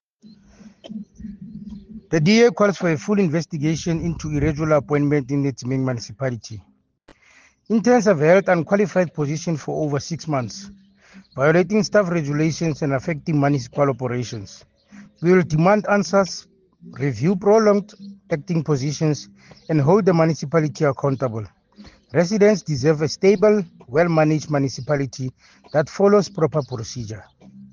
Sesotho soundbites by Cllr Thabo Nthapo.